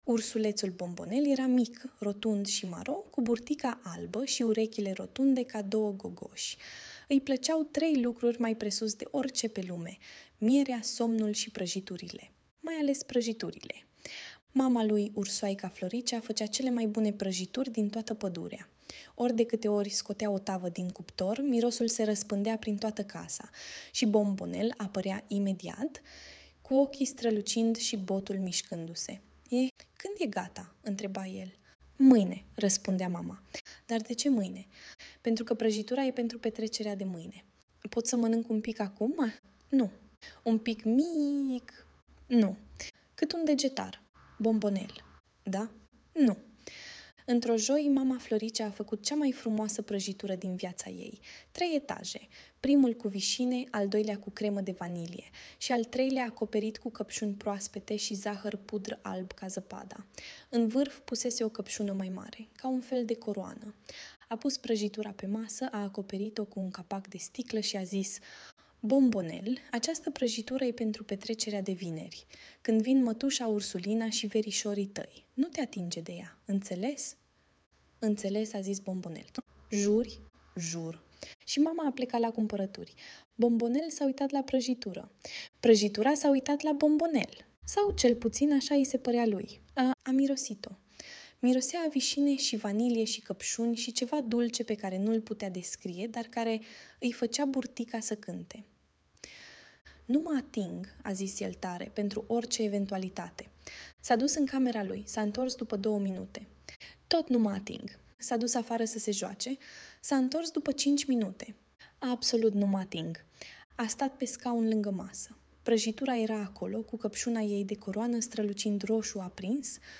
Audiobook Ursulețul Bombonel și prăjitura dispărută